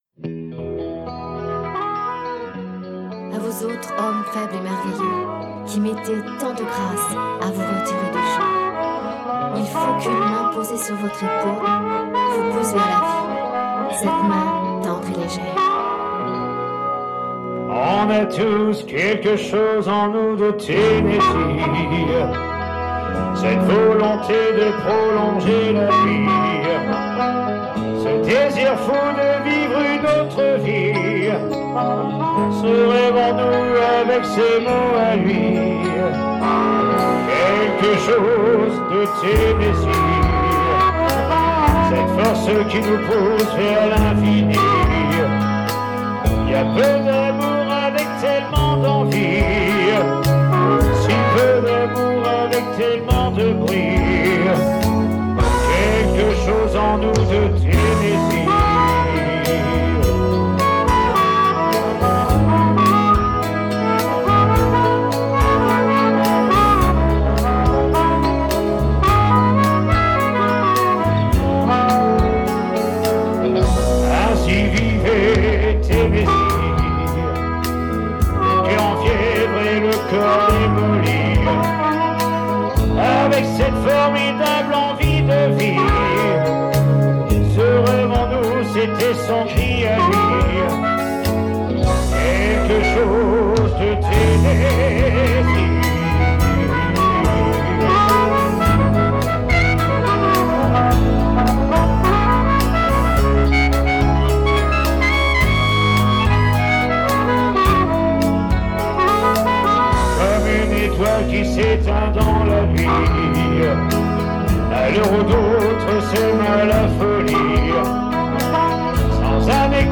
SOIREES BLUES-ROCK RETROSPECTIVE
DUO CHANT/HARMONICA
soiree bistrot d'oliv - 14-12-19